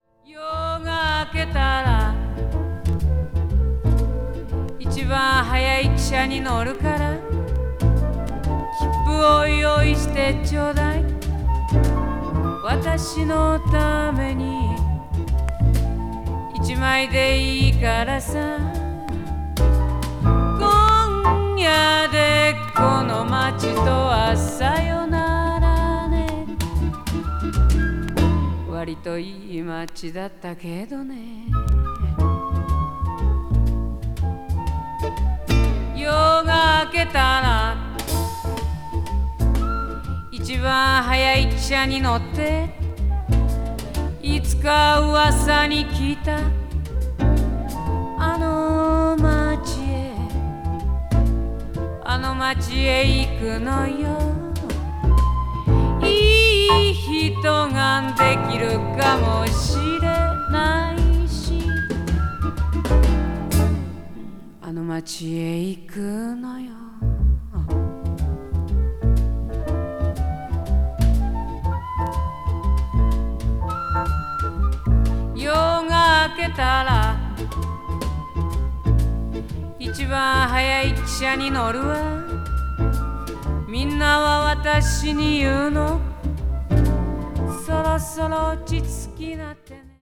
blues   blues rock   folk   folk rock   jazz vocal   soul